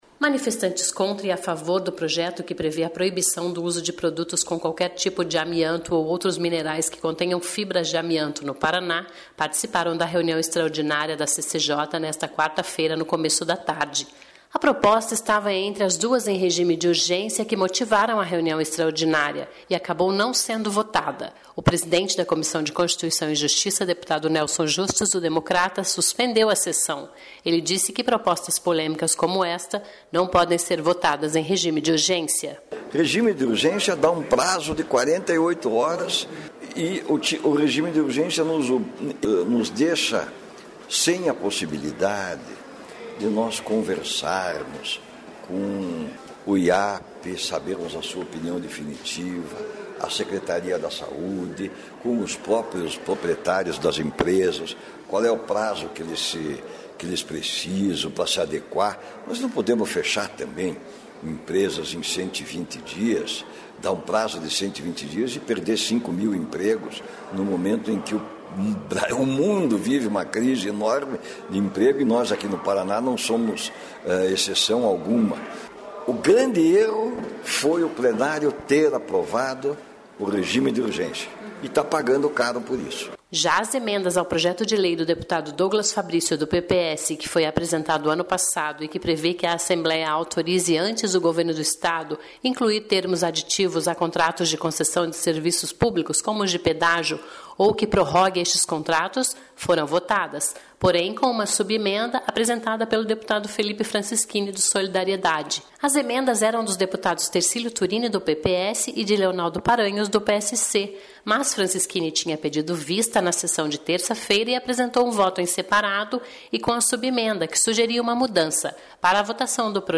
(Sonora)